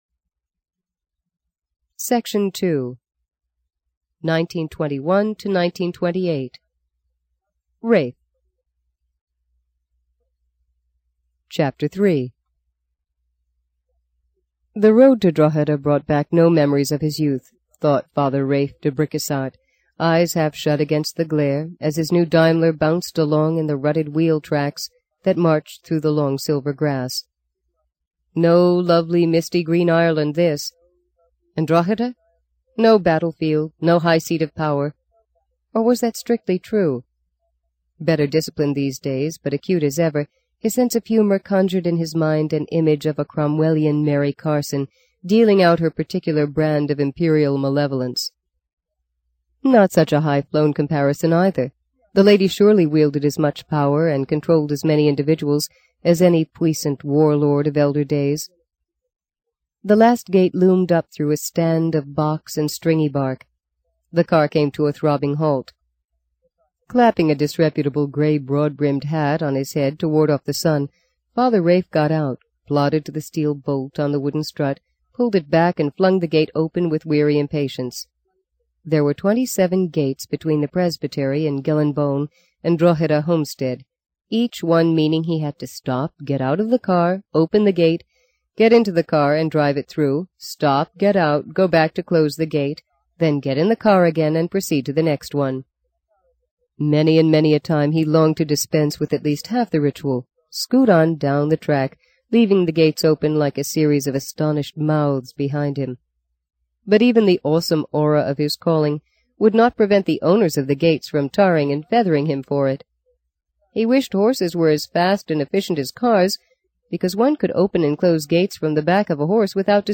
在线英语听力室【荆棘鸟】第三章 01的听力文件下载,荆棘鸟—双语有声读物—听力教程—英语听力—在线英语听力室